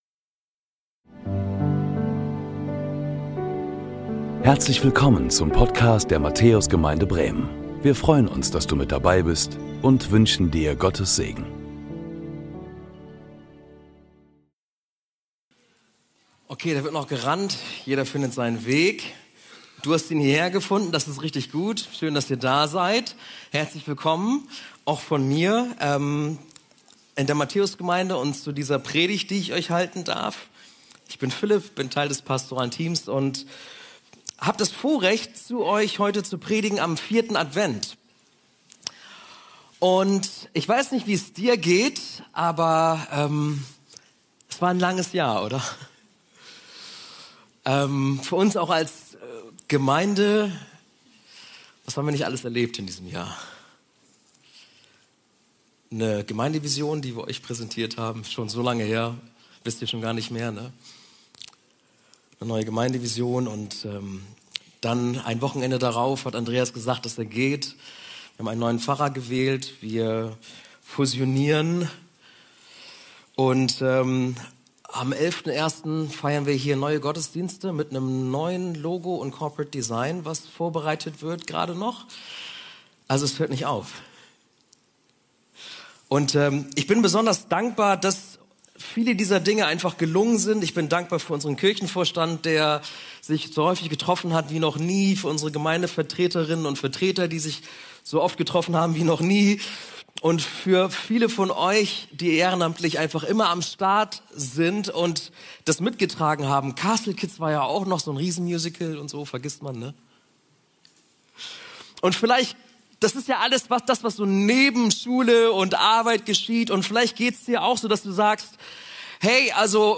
Willkommen zum 12 Uhr Gottesdienst aus der Matthäus Gemeinde Bremen!